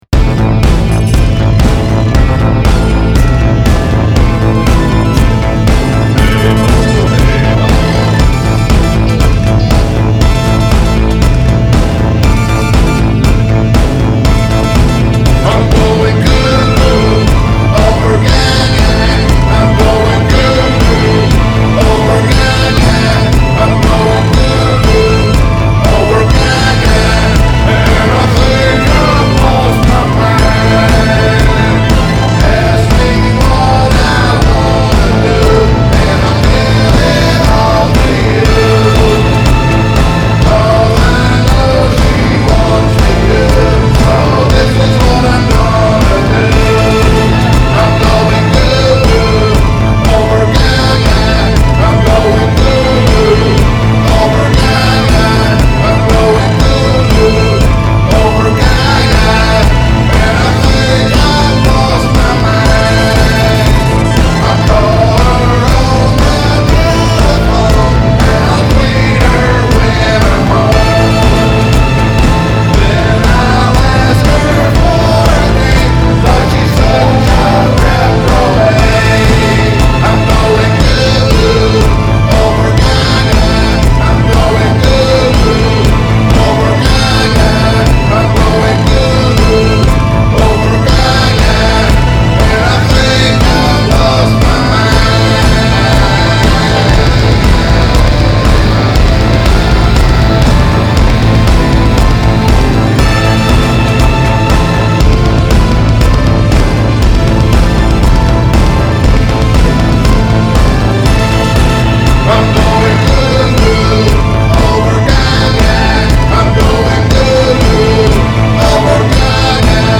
119 beats per minutes tempo